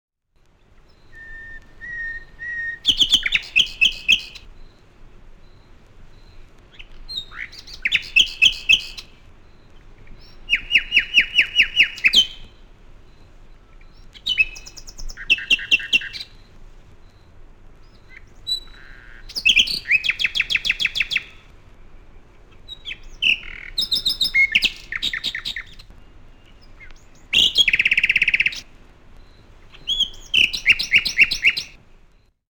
SLÁVIK KROVINOVÝ
Je to hlas najslávnejšieho speváka spomedzi našich vtákov, slávika krovinového. Ozýva sa cez deň aj v noci. Je nenápadný, je ťažké ho spozorovať, zato počuť ho je dobre, má veľmi silný hlas.